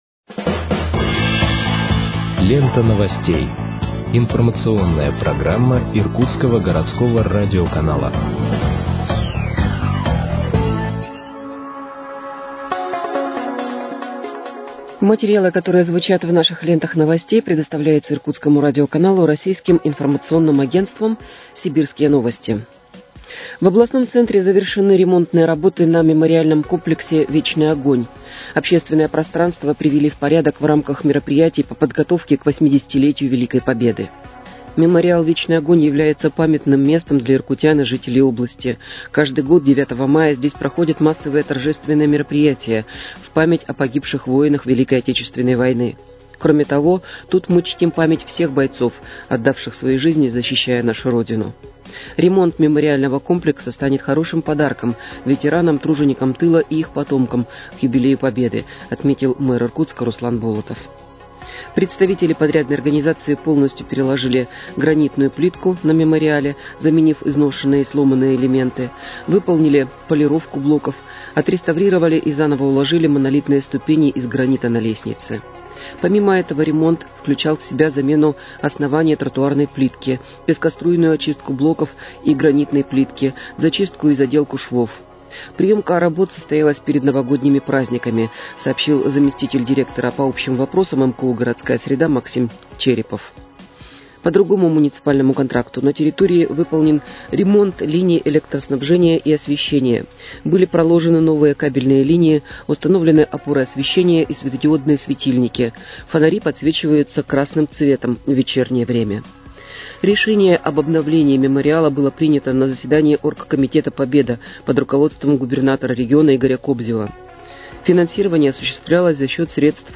Выпуск новостей в подкастах газеты «Иркутск» от 15.01.2025 № 1